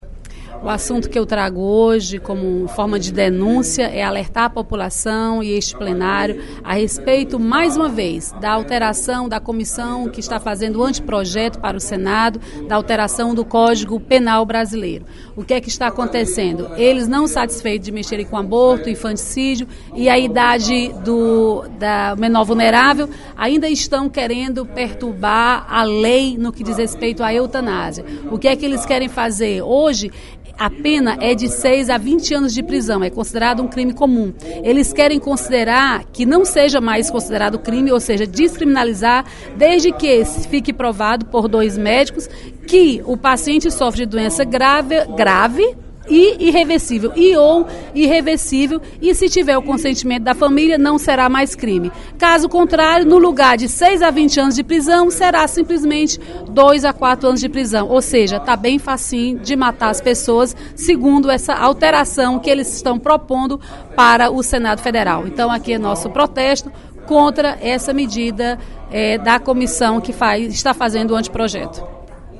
Na sessão plenária desta quarta-feira (11/04), a deputada Dra.Silvana (PMDB) ocupou a tribuna da Assembleia Legislativa, para criticar a mudança do Código Penal que pretende descriminalizar a eutanásia.